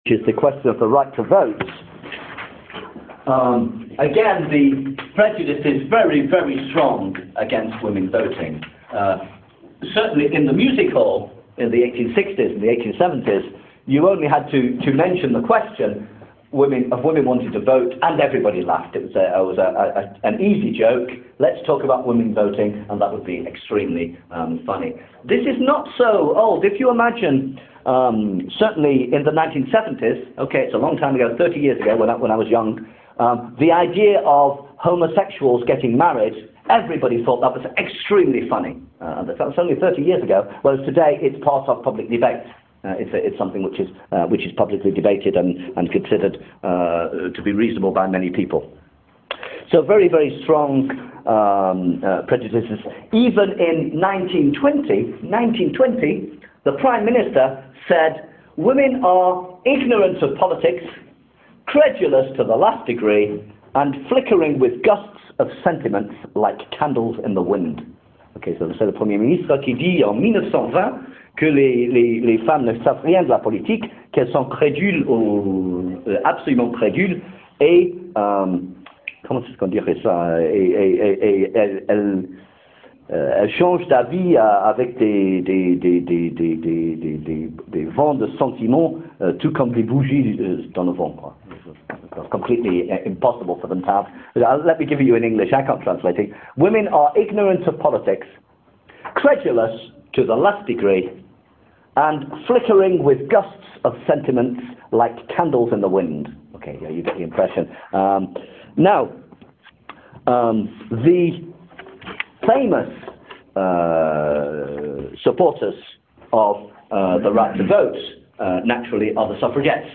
You will find on this page some extracts from classes concerning some of the most important movements.